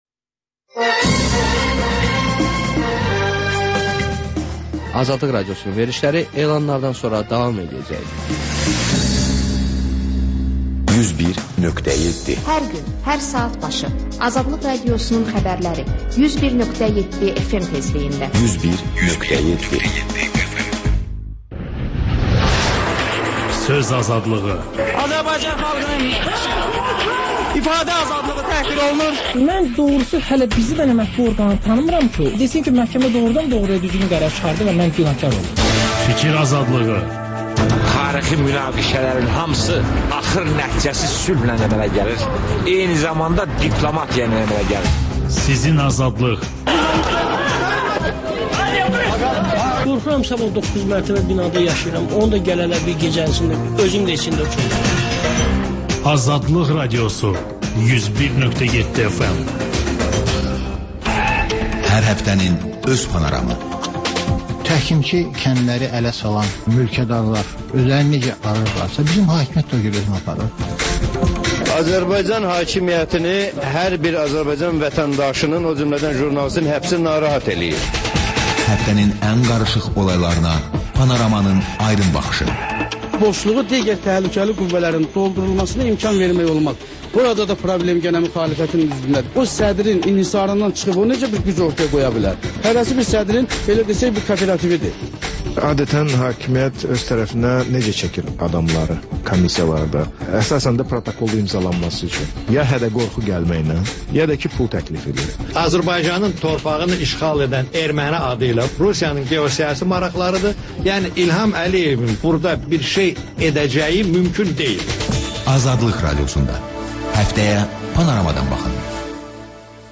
Xəbərlər, HƏMYERLİ: Xaricdə yaşayan azərbaycanlılar haqda veriliş, sonda MÜXBİR SAATI: Müxbirlərimizin həftə ərzində hazırladıqları ən yaxşı reportajlardan ibarət paket